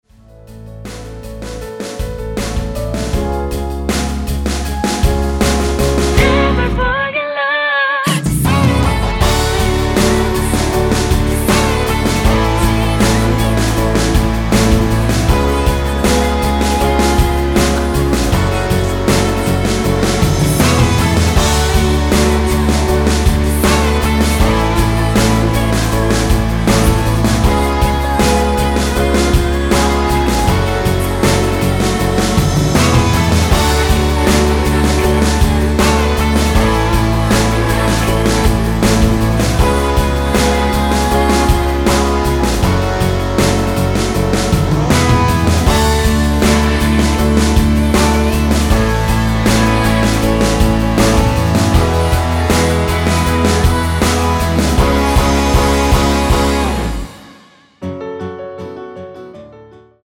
원키코러스 포함된 MR입니다.
F#m
앞부분30초, 뒷부분30초씩 편집해서 올려 드리고 있습니다.